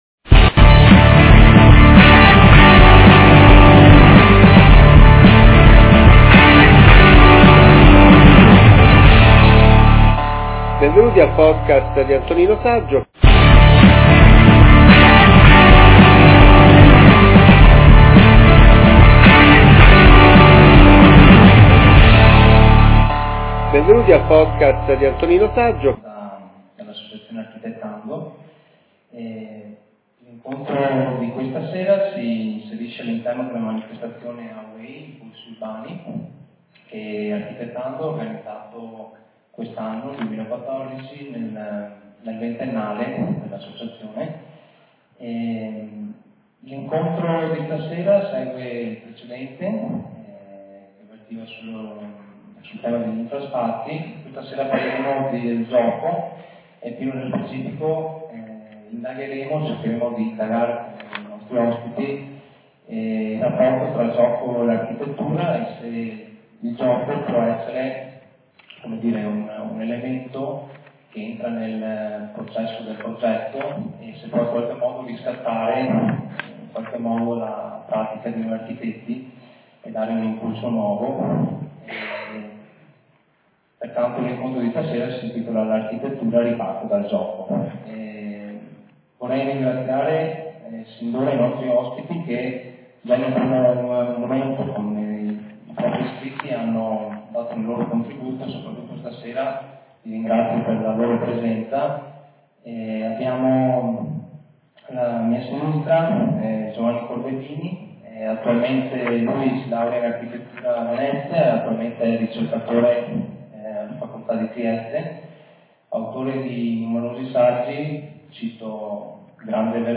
Ascolta l'audio dell'intera conferenza